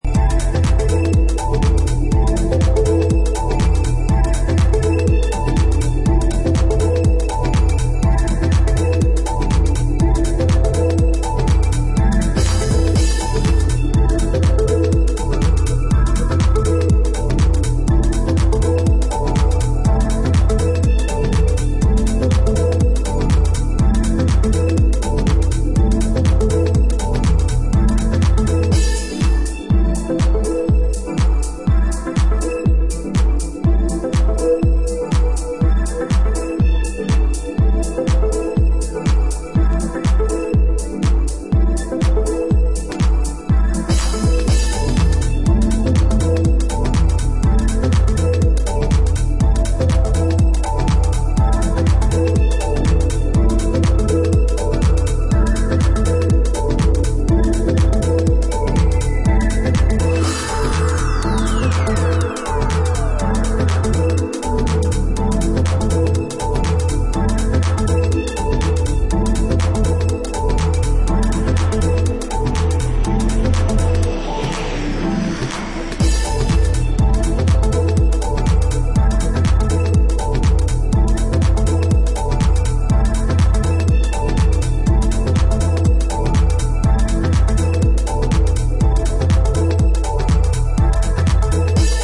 dub mix